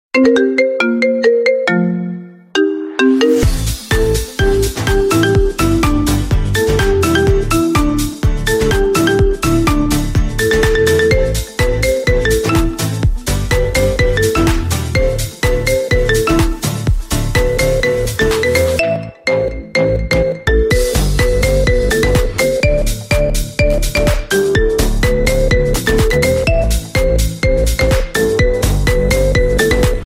Kategorien Marimba Remix